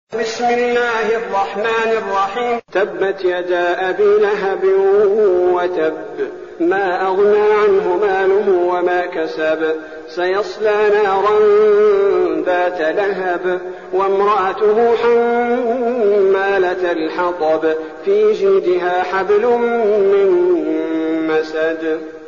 المكان: المسجد النبوي الشيخ: فضيلة الشيخ عبدالباري الثبيتي فضيلة الشيخ عبدالباري الثبيتي المسد